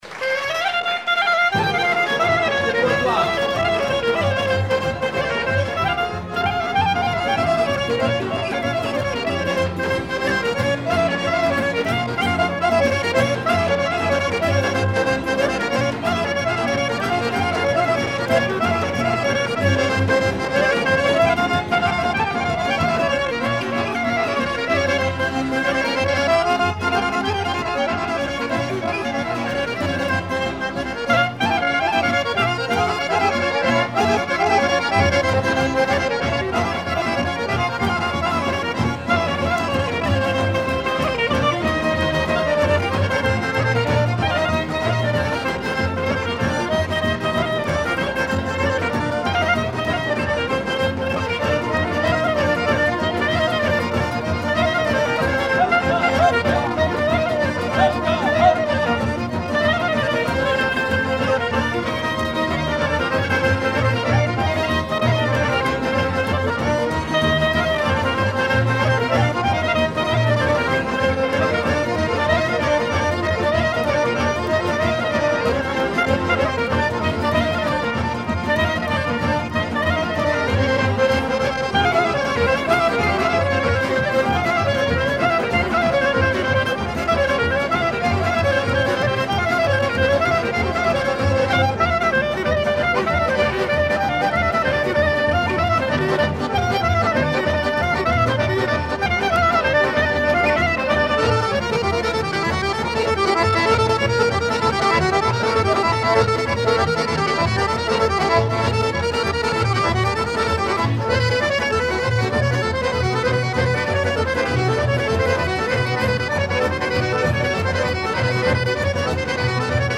Horo
danse : horo (Bulgarie)
Pièce musicale inédite